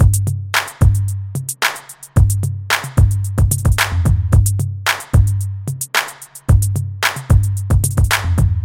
标签： 111 bpm Hip Hop Loops Piano Loops 2.91 MB wav Key : A
声道立体声